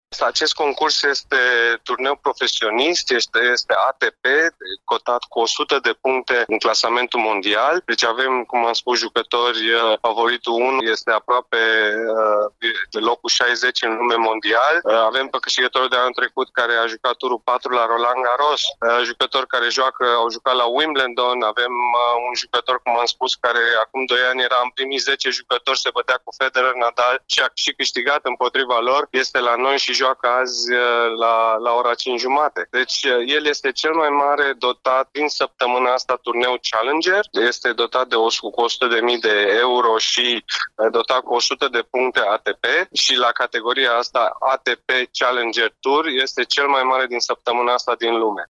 Directorul evenimentului, Andrei Pavel a precizat că turneul este finanţat cu o sută de mii de euro și este cel mai mare și mai important turneu în desfășurare.